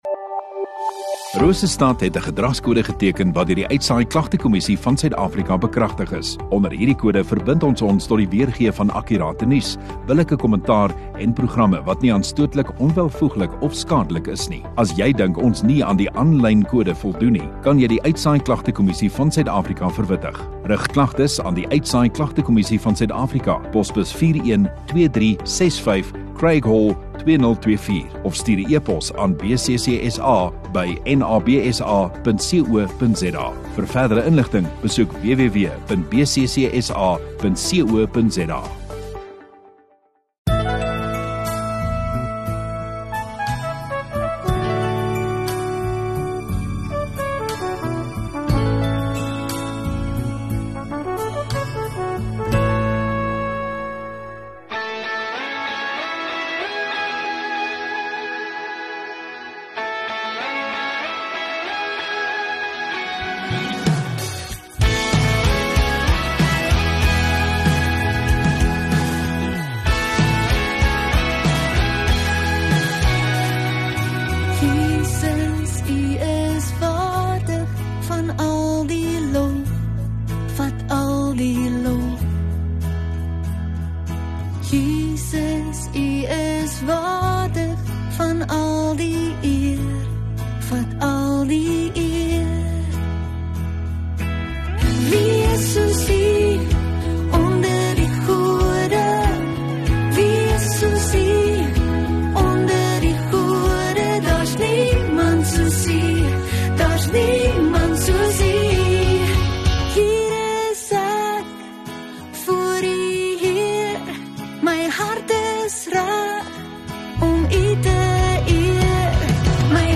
13 Oct Sondagaand Erediens